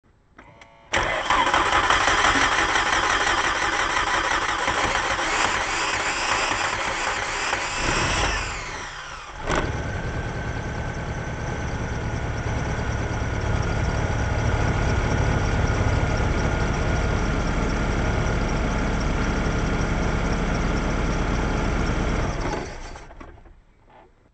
Die Eckdaten: Corrado G 60 Baujahr 1990 gewechselter Anlasser (gebrauchtes Originalteil) Seit einiger Zeit dreht der Anlasser beim starten aber der Motor springt erst an, wenn ich das Gas…